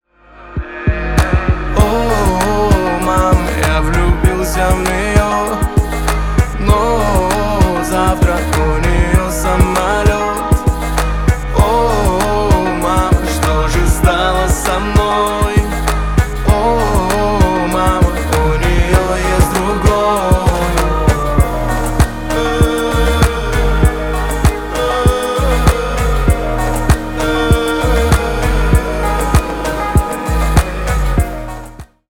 Поп Музыка
грустные # тихие